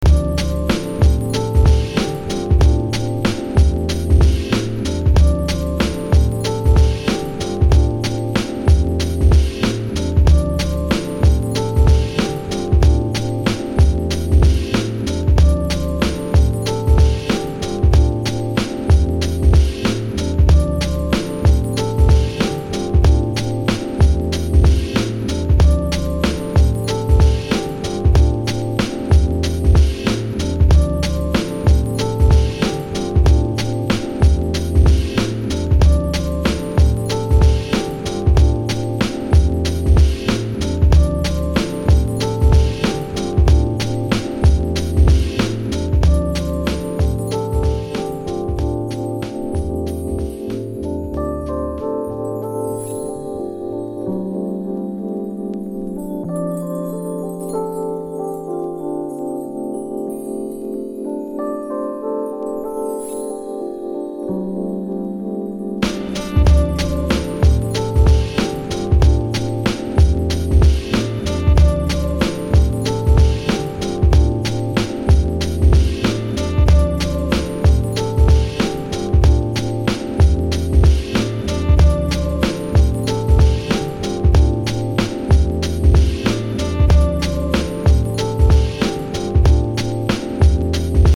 jazzy electric piano low beat tune